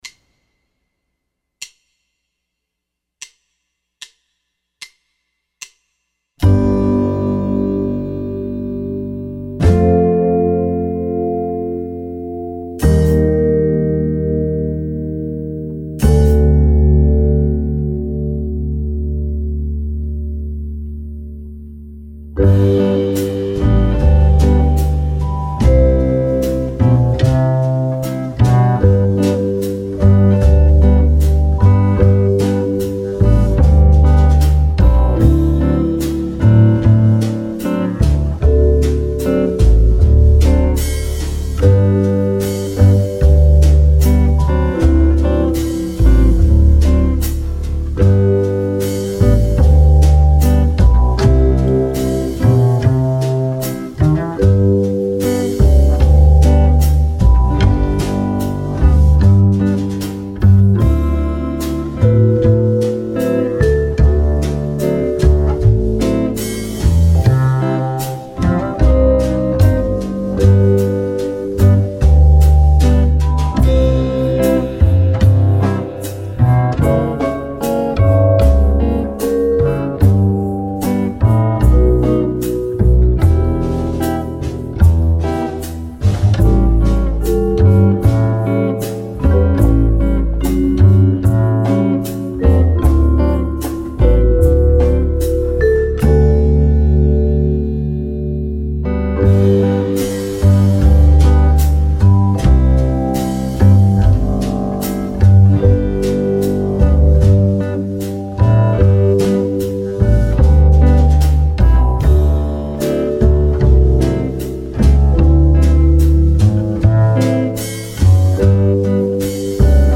Backing Track MM=75